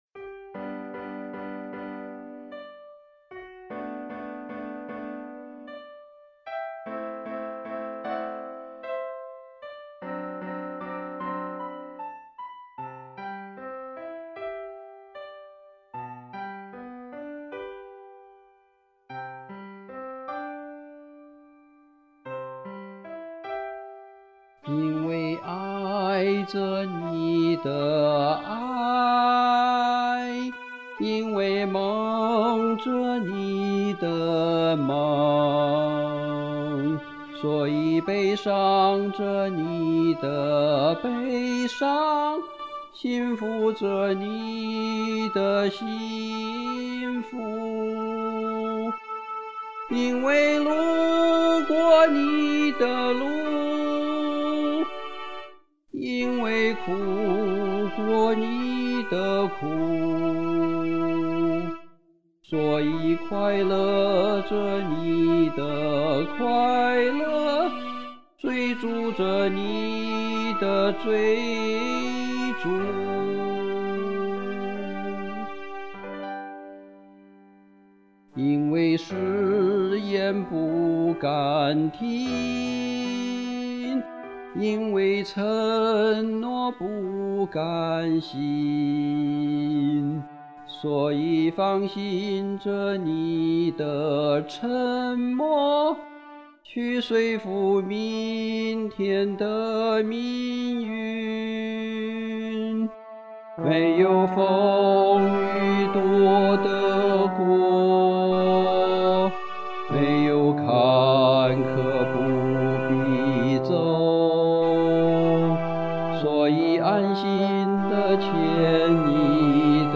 伴奏是用MuseScore软件做的。